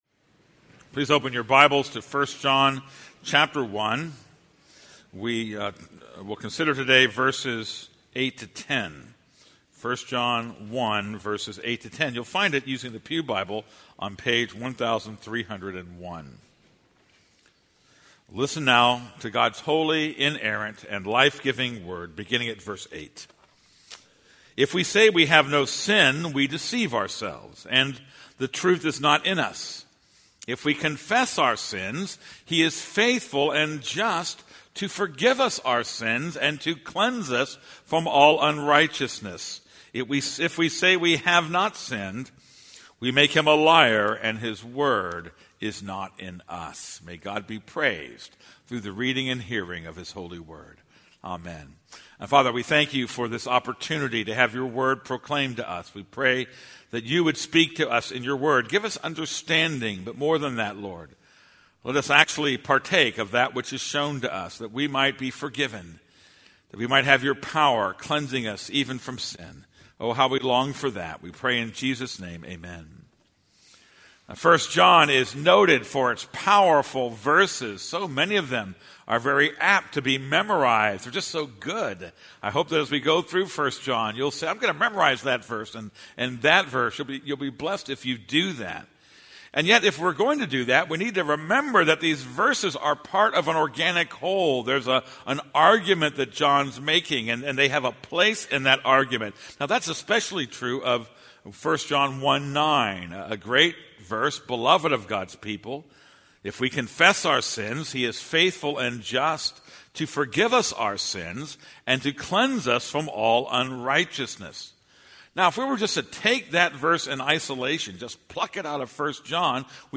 This is a sermon on 1 John 1:8-10.